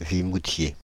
Vimoutiers (French pronunciation: [vimutje]
Fr-Paris--Vimoutiers.ogg.mp3